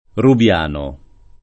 [ rub L# no ]